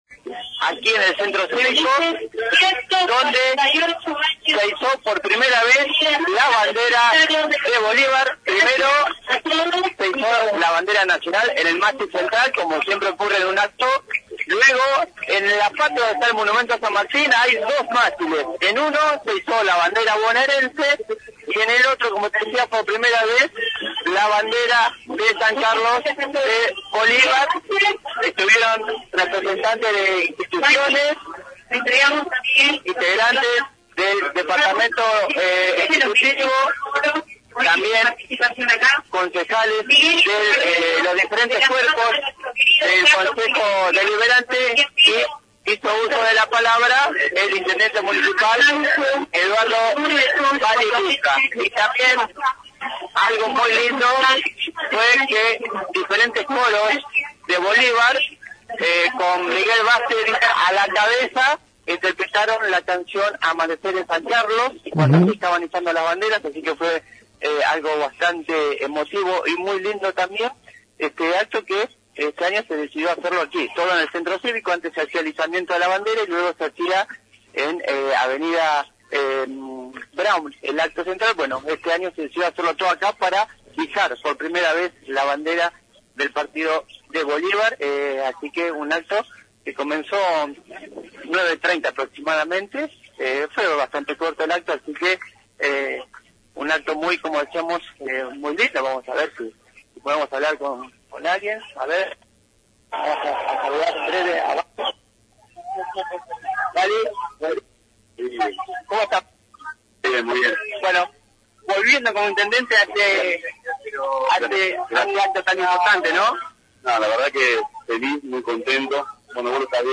Fin del Acto por los 148 Años de Bolívar y Entrevistas :: Radio Federal Bolívar